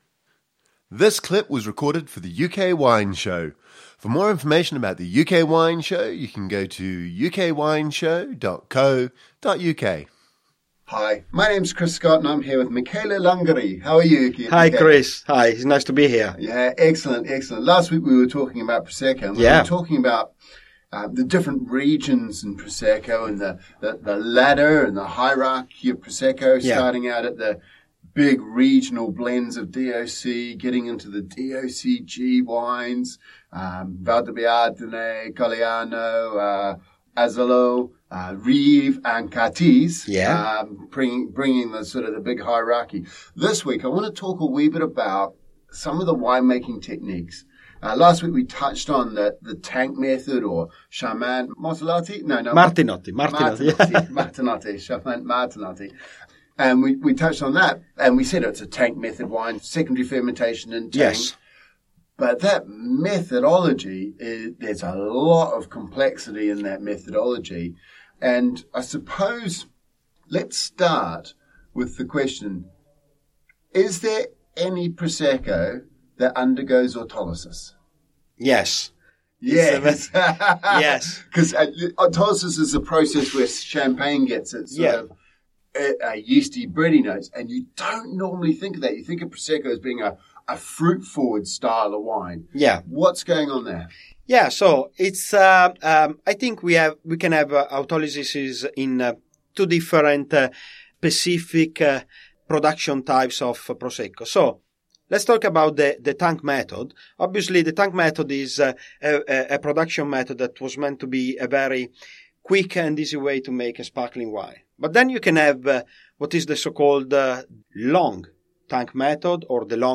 Overview In this second interview we talk about the wine making techniques used in the production of Prosecco.